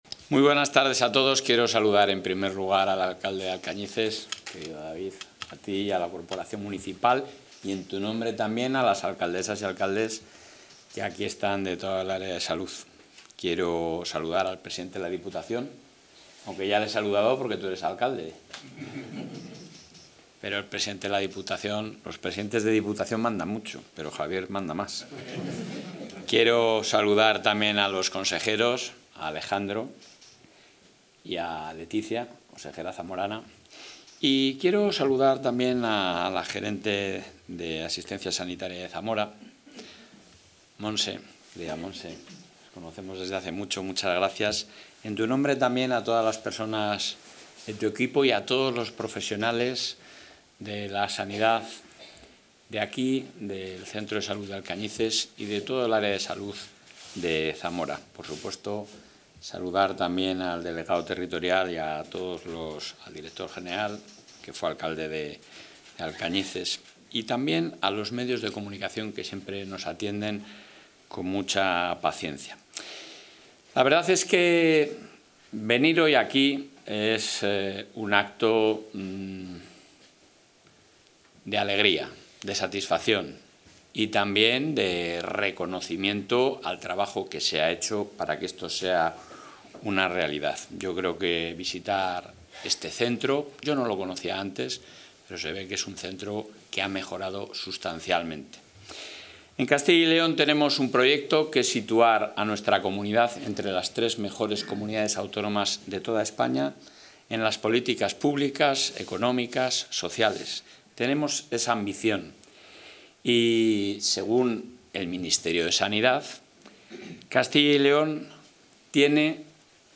Intervención del presidente.
El presidente del Ejecutivo autonómico, Alfonso Fernández Mañueco, ha visitado hoy el Centro de Salud Aliste, en la localidad zamorana de Alcañices, tras una reforma integral llevada a cabo por el Gobierno de Castilla y León, a la que se han destinado un millón de euros.